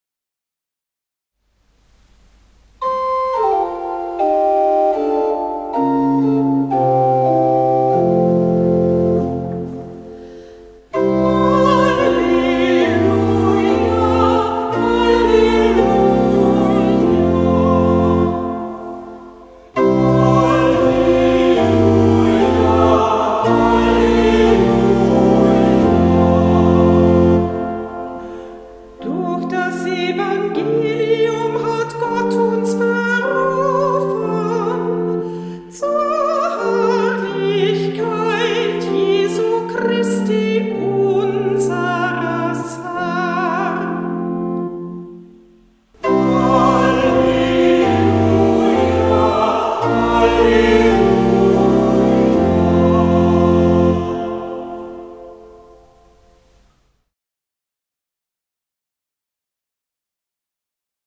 Ruf vor dem Evangelium - September 2024
Kantorin der Verse